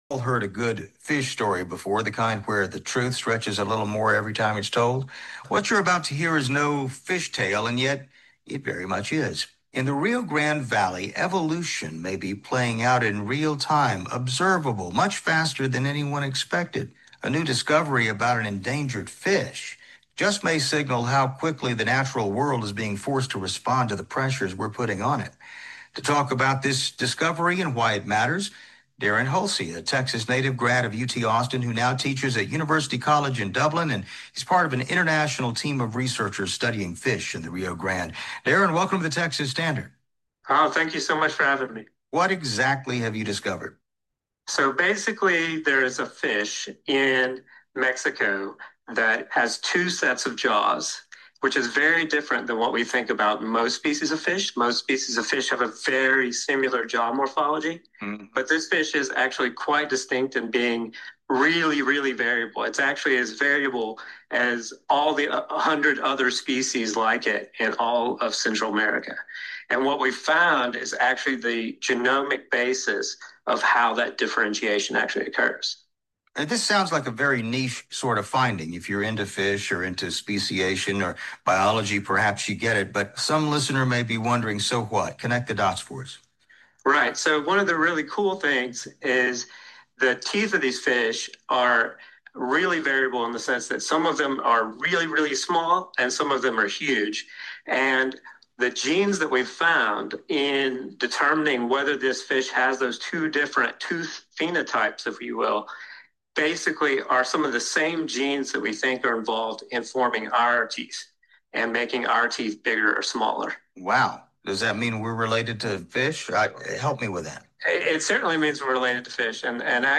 Texas Standard InterviewStudy finds rapid evolution in fish tied to Texas border waters – 9 April 2026 –
kut_fishinterview_9april2026.m4a